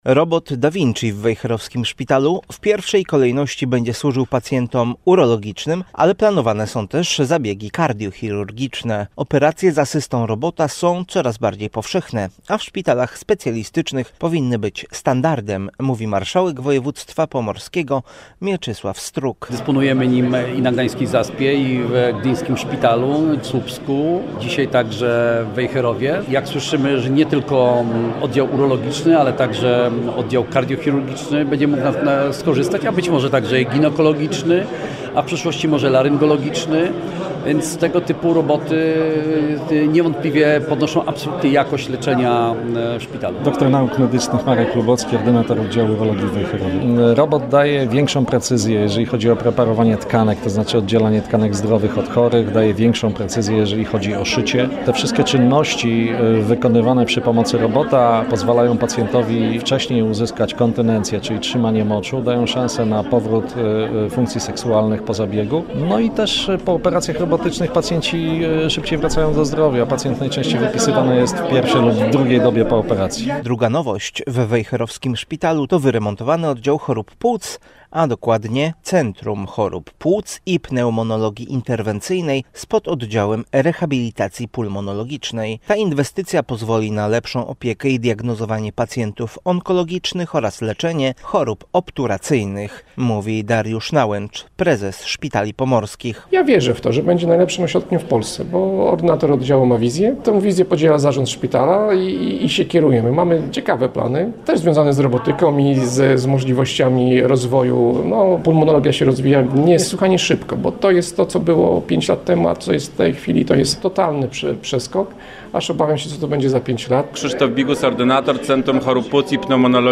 Jak tłumaczył Mieczysław Struk, marszałek województwa pomorskiego, operacje z asystą robota są coraz bardziej powszechne, a w szpitalach specjalistycznych powinny być standardem.